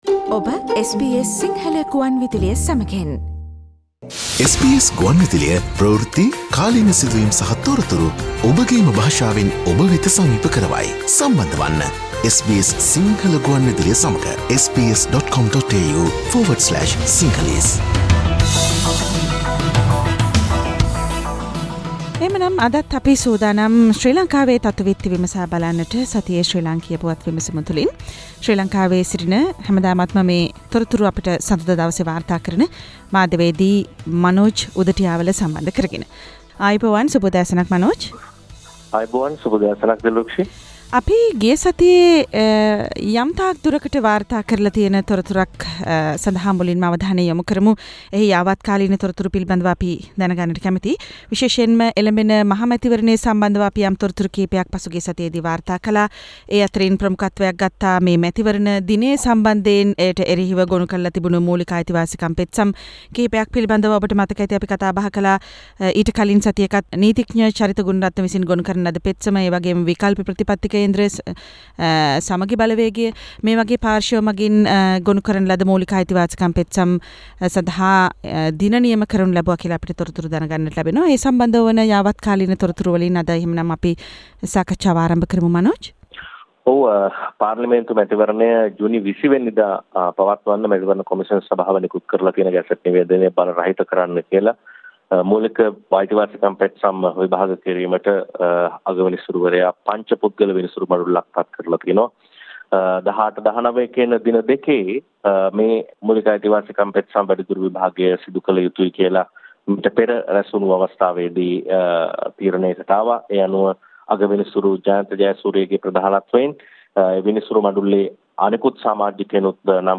President Gota's 3 month salary donation and Ratnajivan Hoole underhand move in the election commission : Weekly Sri Lanka news wrap